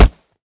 kick3.ogg